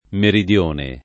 meridione [ merid L1 ne ]